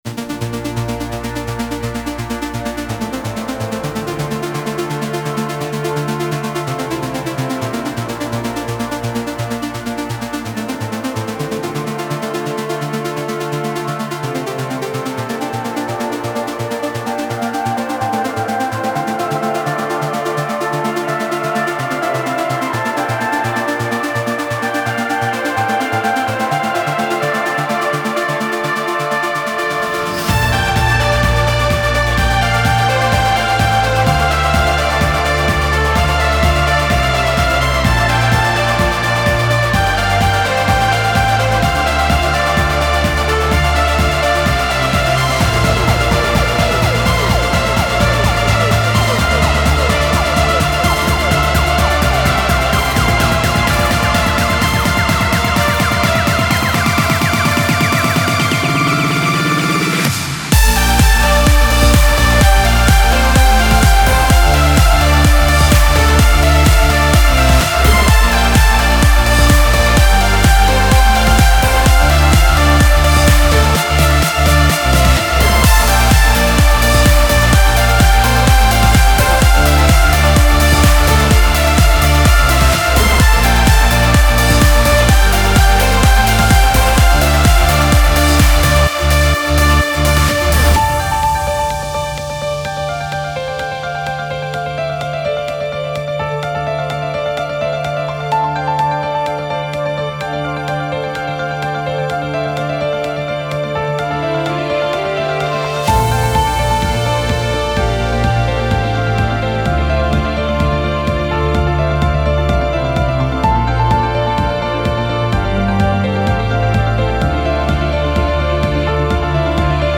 • Жанр: Dance, House